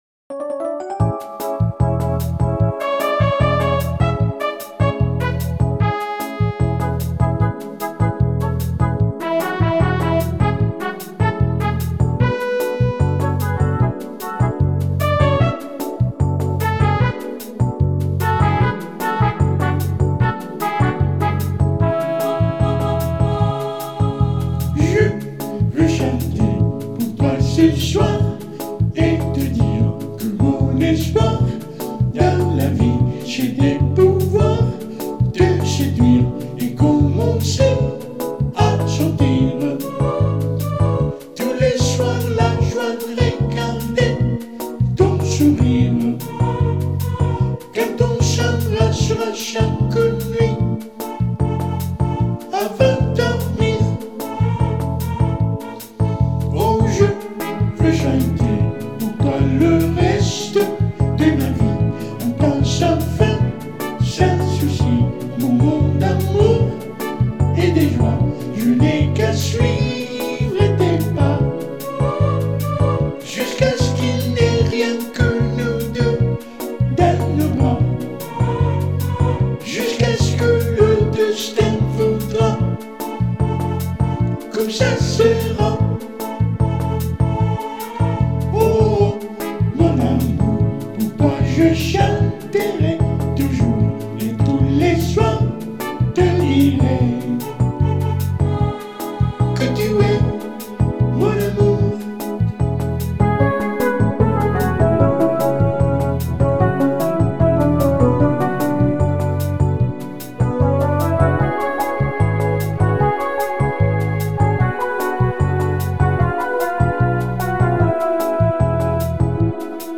Most recordings were done "at home" in some of the cities where I've lived during my latest travels.
NOTE: The high voice you will hear is just me singing in falsetto style, just like the Bee Gees, Crosby, Stills & Nash and others used to do.
To record the vocals I use a BOSS BR-800, a portable mini digital recorder.